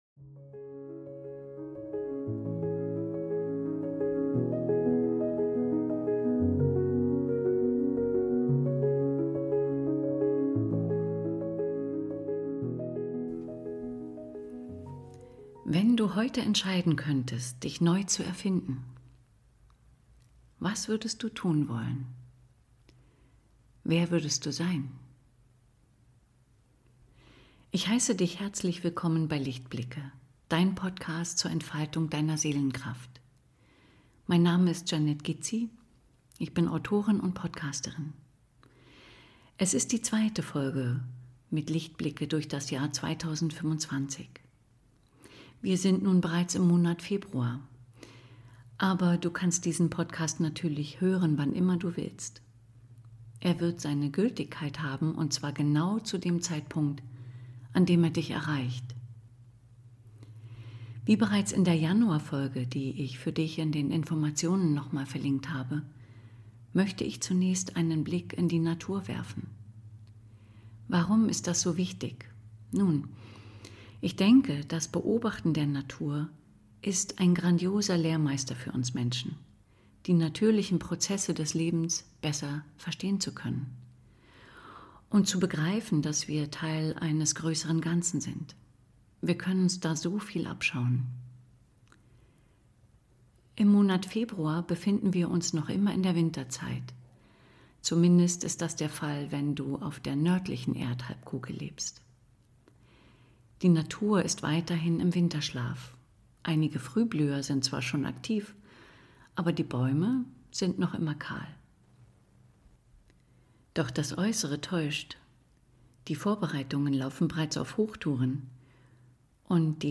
Der Monat Februar hat zum Thema: Erfinde dich neu!Dabei steht im Vordergrund, Veränderungen im Leben anstatt mit Anstrengung und harter Arbeit, durch den Weg der Freude, umzusetzen. In einer geführten Meditation (ab 07:35) kannst du auf eine Entdeckungsreise durch dein Leben gehen und erhältst Impulse, wieder mehr Leichtigkeit und Freude in deinen Alltag zu integrieren.